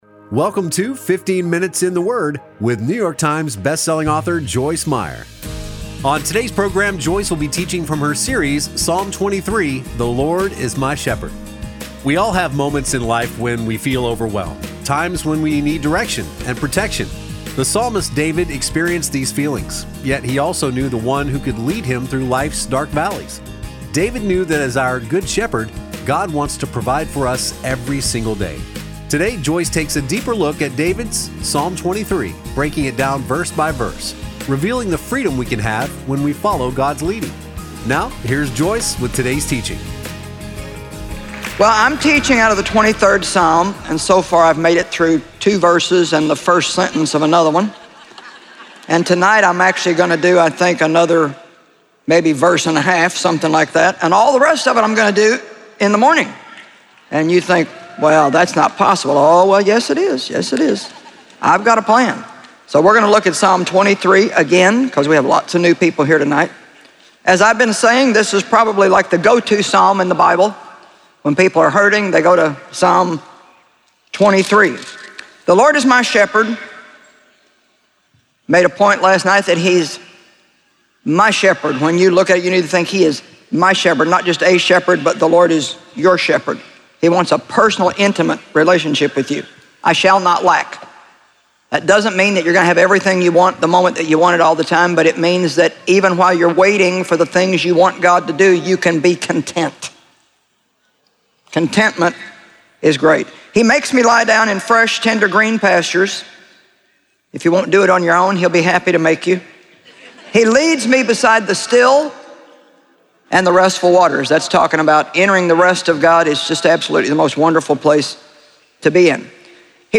Joyce Meyer teaches on a number of topics with a particular focus on the mind, mouth, moods and attitudes. Her candid communication style allows her to share openly and practically about her experiences so others can apply what she has learned to their lives.